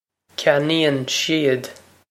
Pronunciation for how to say
kyan-een shiv
This is an approximate phonetic pronunciation of the phrase.
This comes straight from our Bitesize Irish online course of Bitesize lessons.